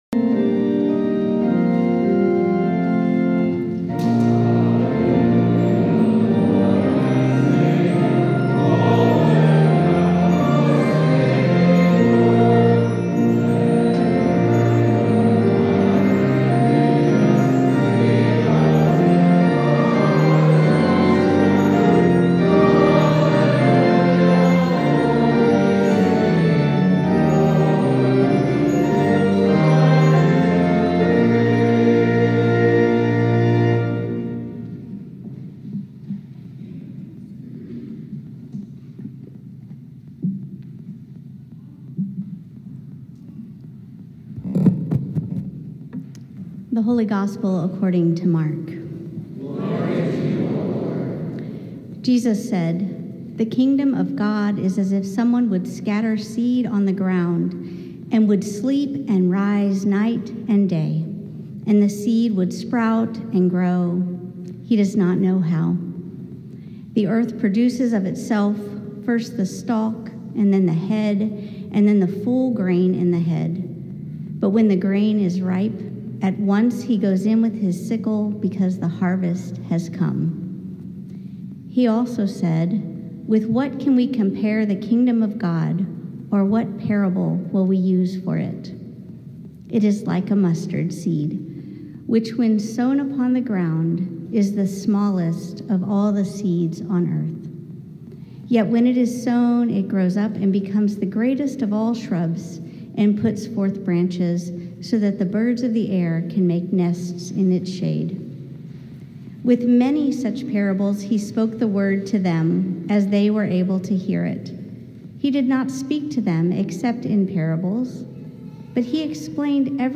Sermon from the Fourth Sunday After Pentecost